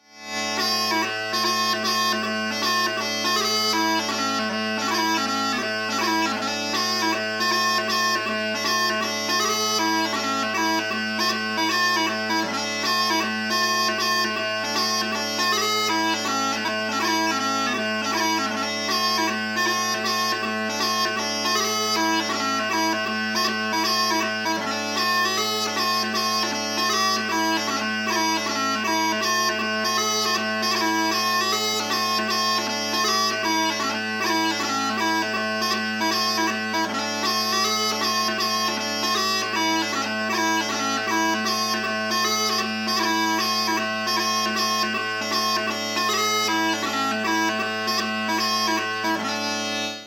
Category: Hornpipe Tag: 2/4
Nice wee, jumpy hornpipe.